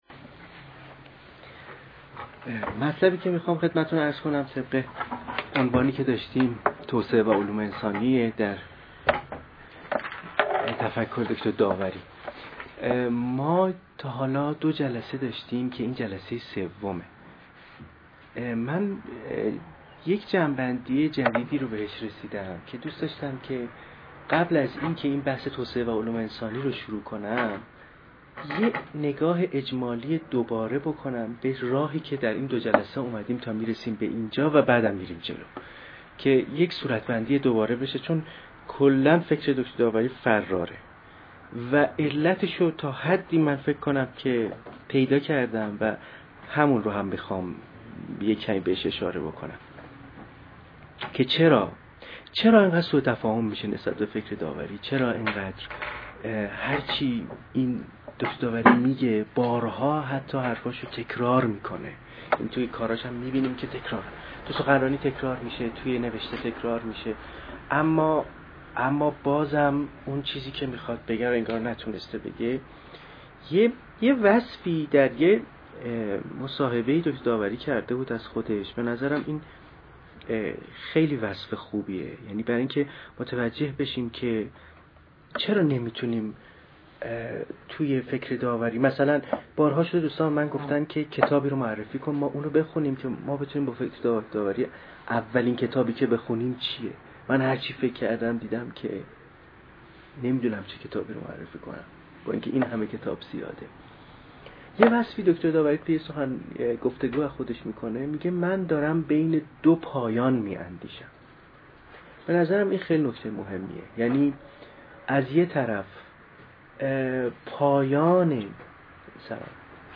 نشست